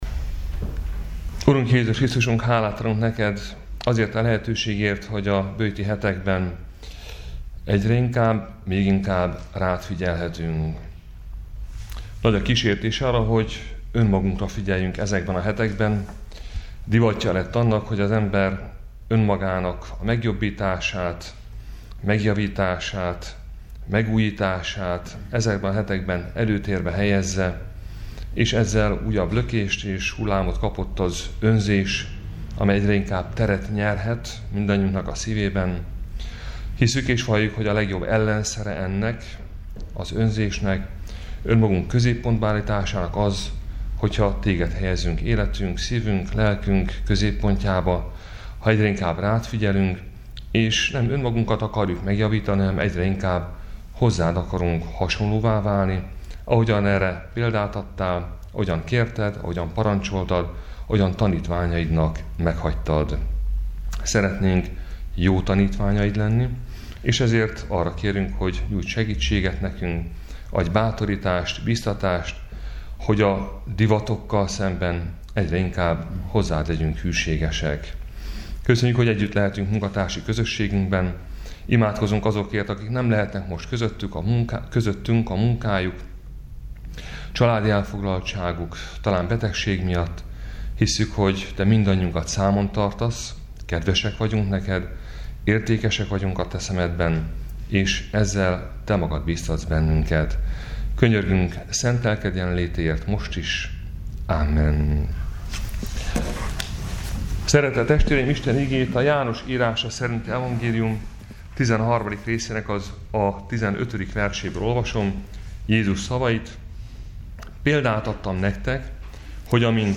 Áhítat, 2019. március 20.